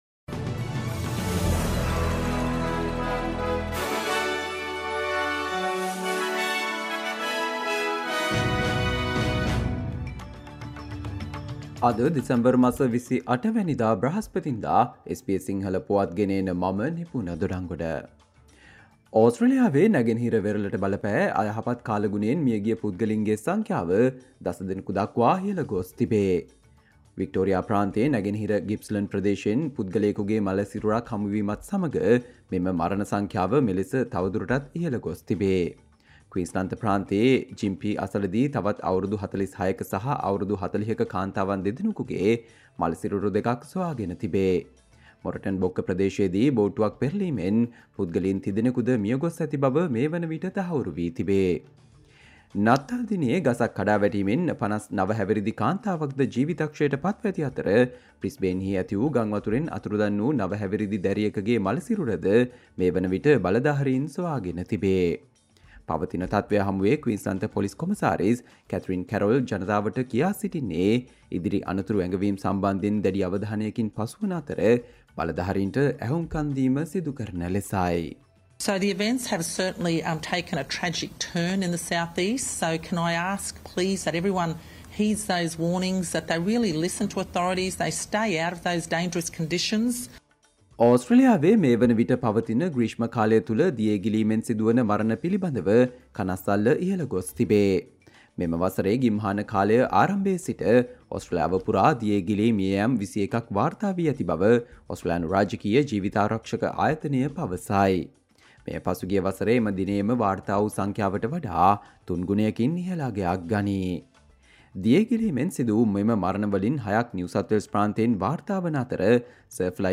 Australia news in Sinhala, foreign and sports news in brief - listen, Thursday 28 December 2023 SBS Sinhala Radio News Flash